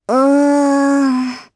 Requina-Vox-Deny_jp.wav